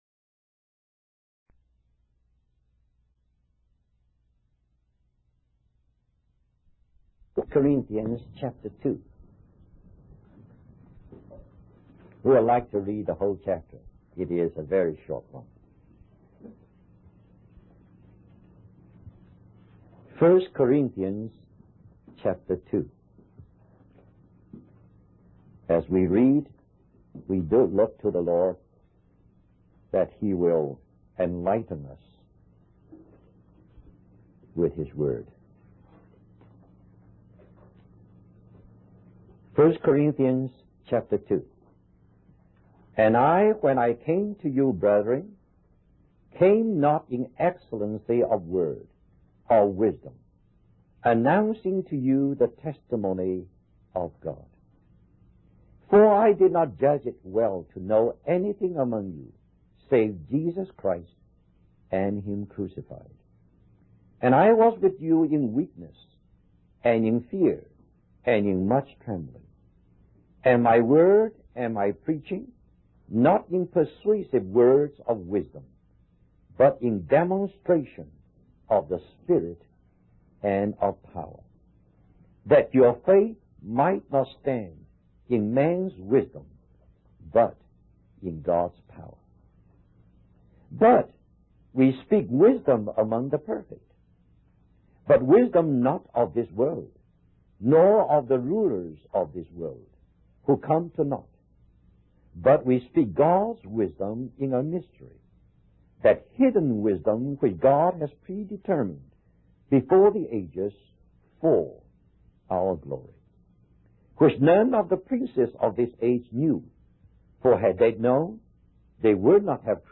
In this sermon, the speaker emphasizes the importance of preaching Jesus Christ and Him crucified. He highlights how often preachers rely on their own eloquence and persuasive words, rather than depending on the power of God.